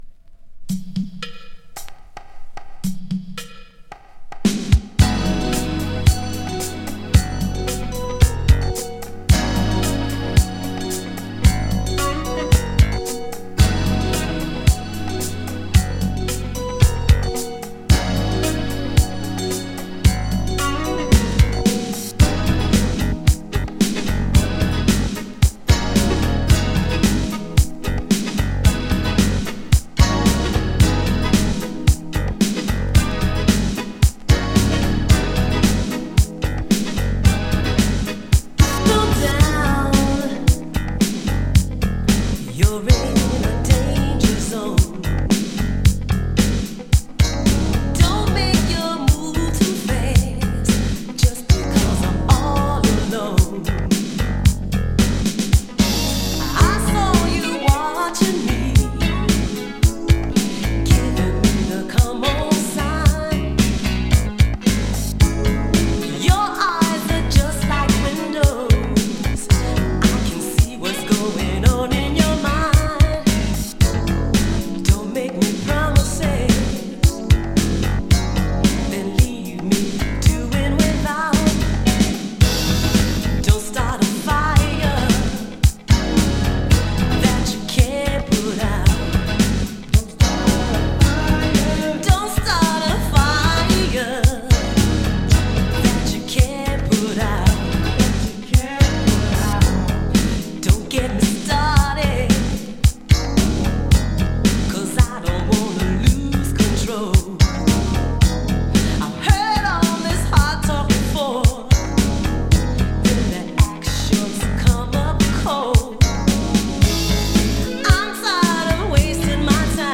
アトランタ産アーバン・ブギー！
【BOOGIE】